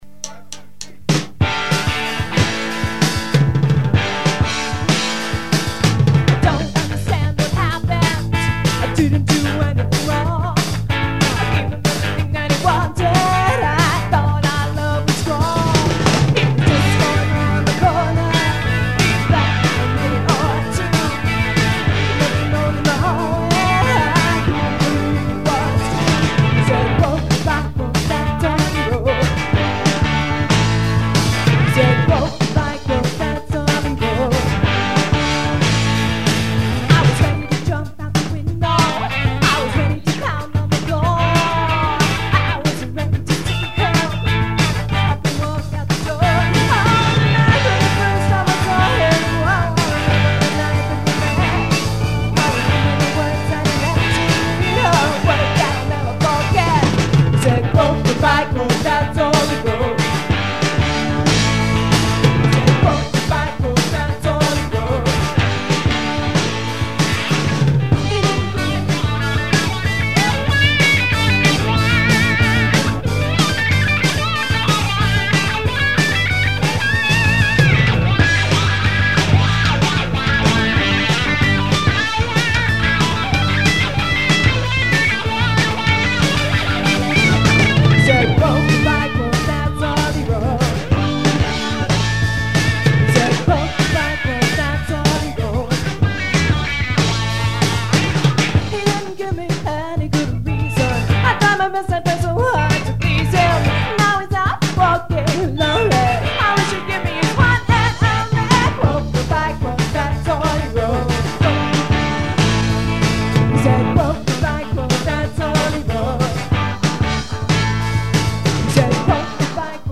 Sensible Shoes was a pop/rock/new wave band I played in in Boston in the early 1980s.
This collection comes from a show we played at the Rathskeller, in Kenmore Square, on Oct 29, 1982.